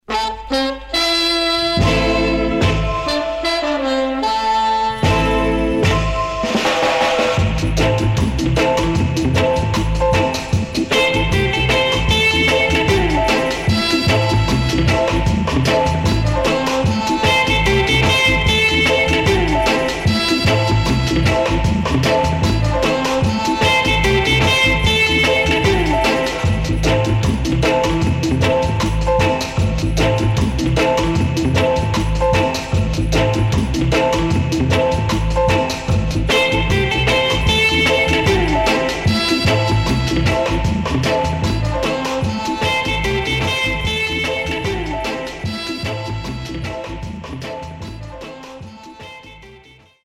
激渋いホーンのイントロから始まるKiller Early Reggae Vocal